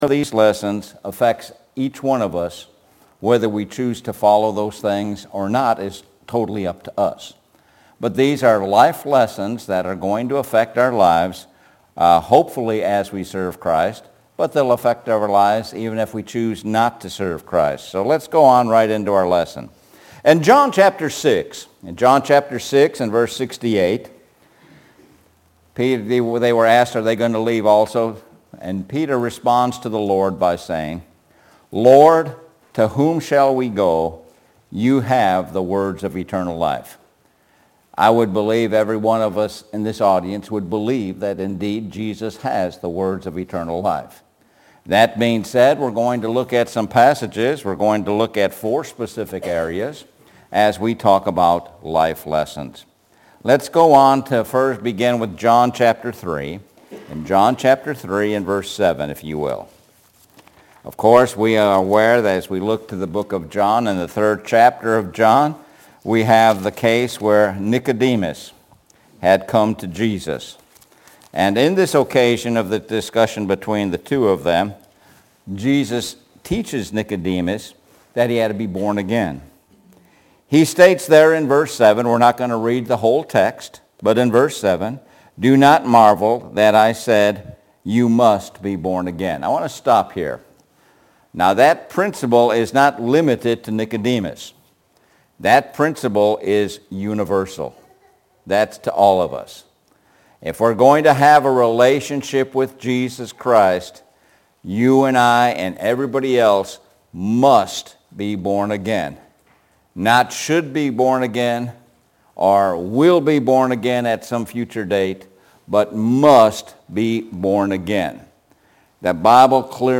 Sun PM Bible Study – Life Lessons